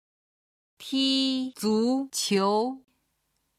踢足球　(tī zú qiú)　サッカーをする